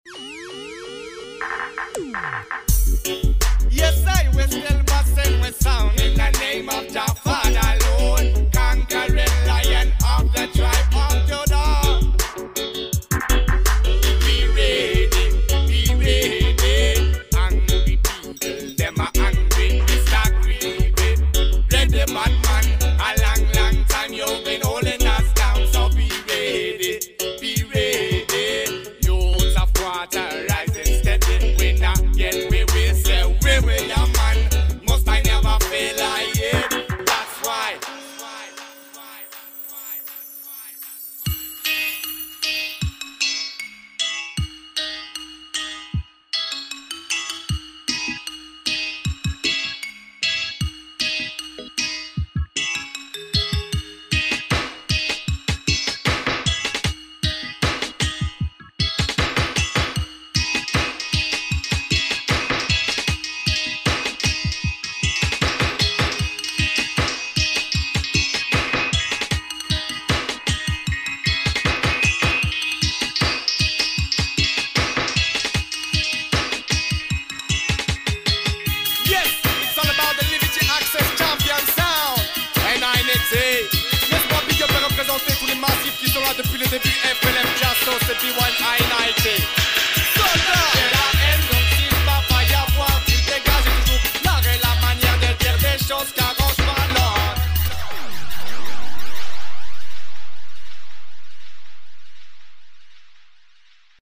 THE BASSLinES